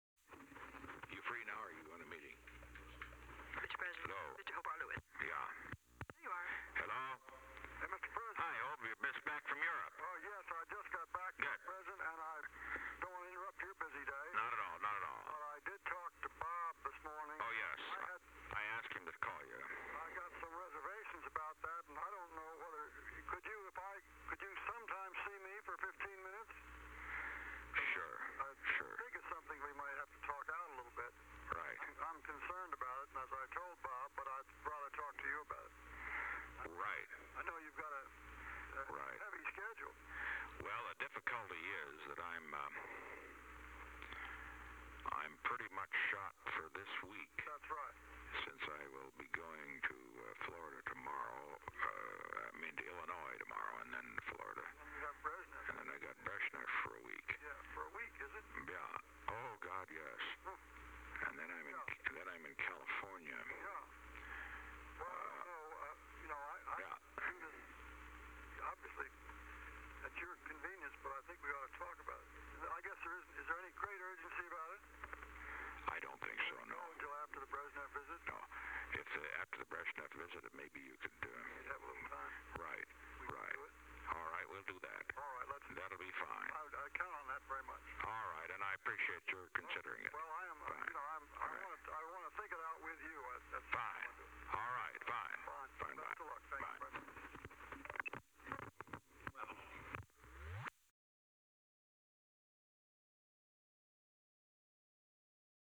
Secret White House Tapes
Conversation No. 40-108
Location: White House Telephone
The President conferred with Alexander M. Haig, Jr.